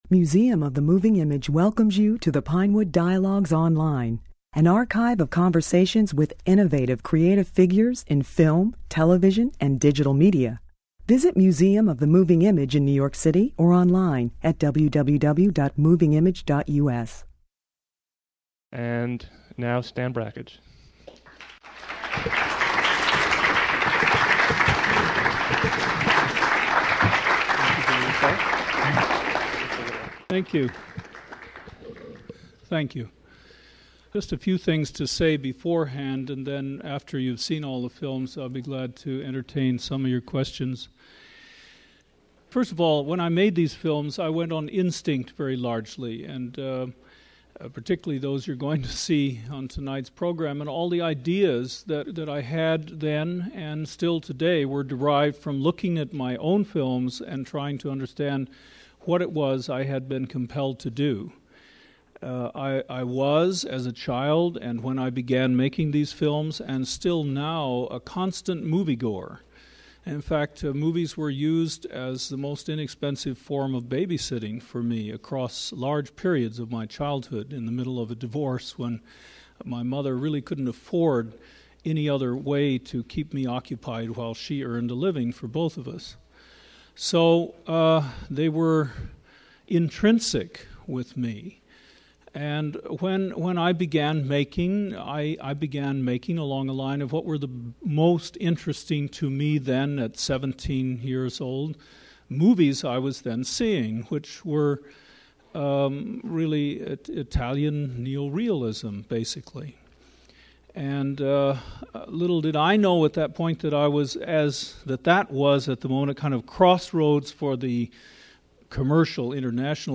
In this talk, opening a month-long retrospective, Brakhage humorously recalled his brief flirtation with Hollywood.